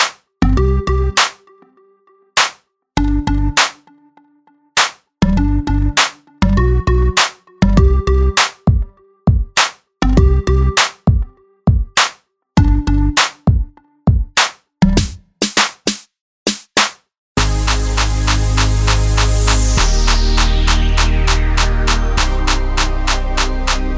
For Solo Female Reggae 3:43 Buy £1.50